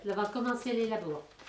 Collectif - ambiance
Catégorie Locution